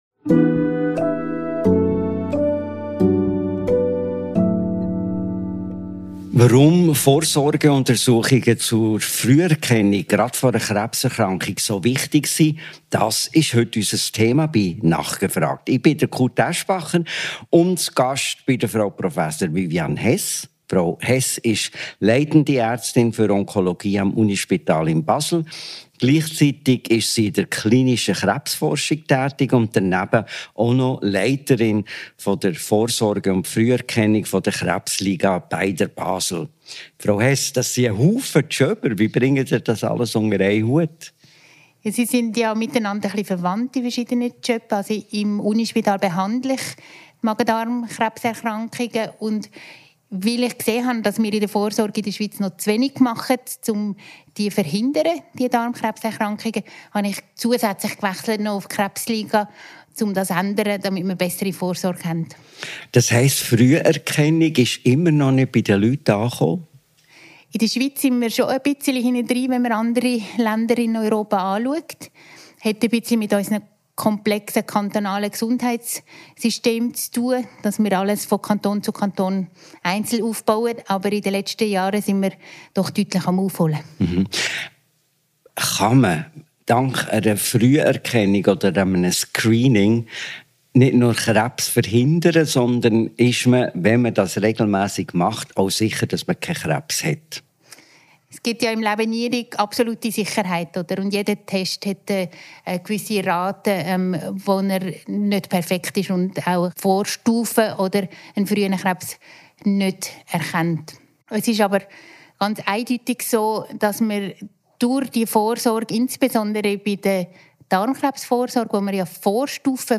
Ein Gespräch über Chancen und Grenzen von Screenings, neue Entwicklungen bei der Prostatakrebs Früherkennung, Lebensstilfaktoren und darüber, warum Vorsorge kein Angstthema sein sollte, sondern aktive Gesundheitsfürsorge.